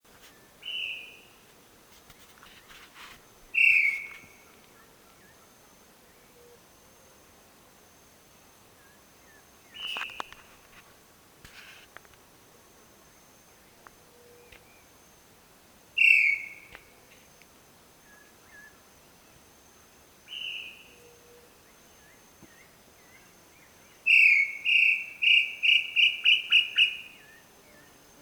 Tataupa Tinamou
Crypturellus tataupa
Records from Parque Provincial Cruce Caballero
Last Vocalizations published
Tataupa-Comun.wma